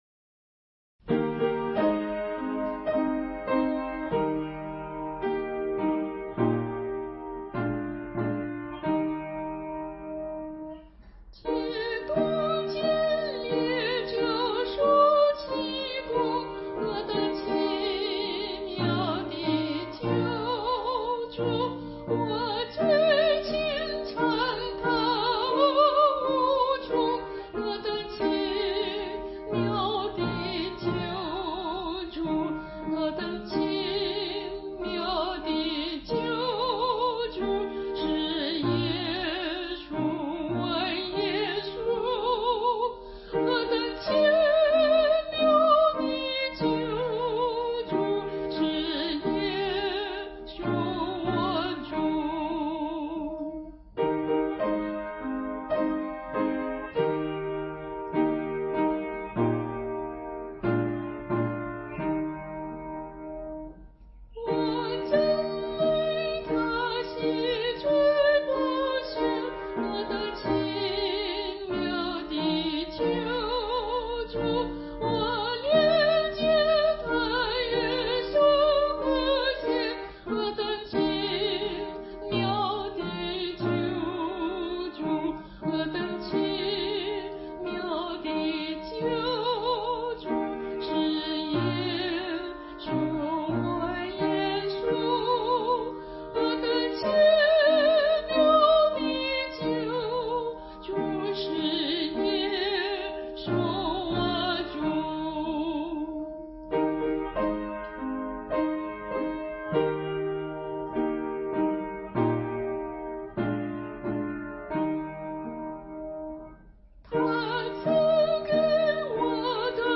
伴奏
诗歌节奏明快，旋律简洁优美，甚受欢迎。